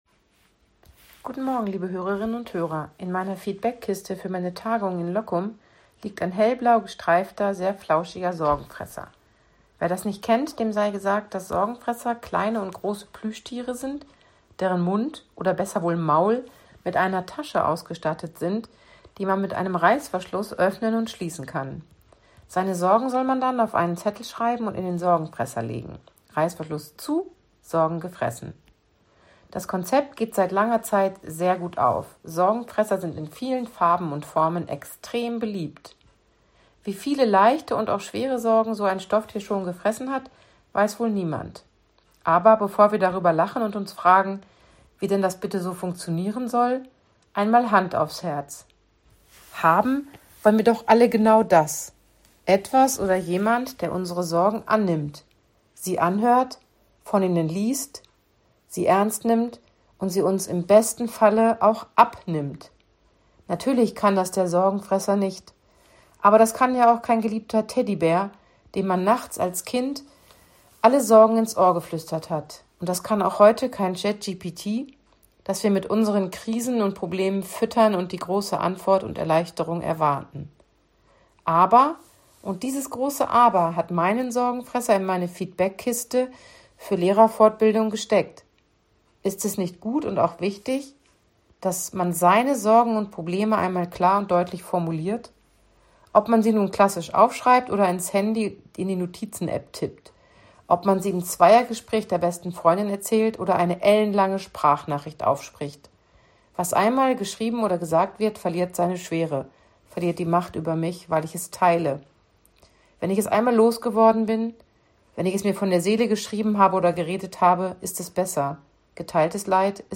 Radioandacht vom 20. August